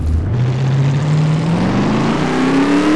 Index of /server/sound/vehicles/lwcars/Detomaso_pantera